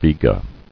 [Ve·ga]